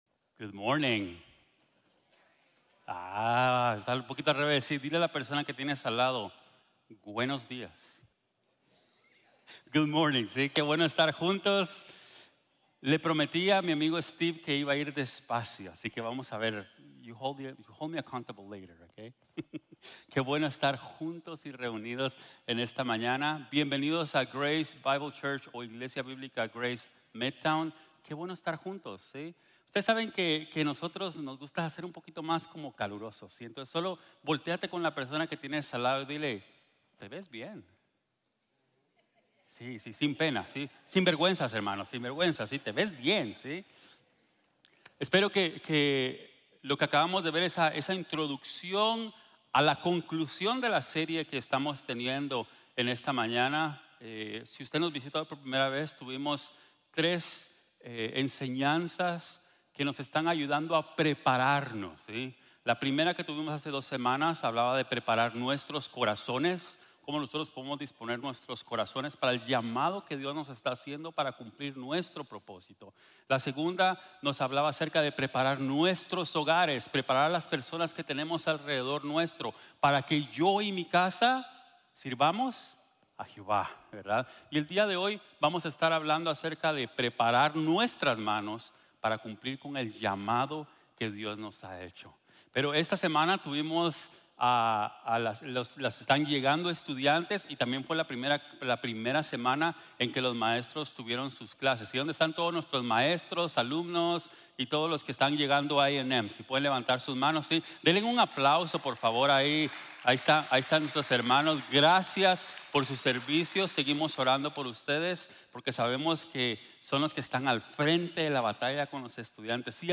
prepara tus manos para cumplir tu llamado | Sermon | Grace Bible Church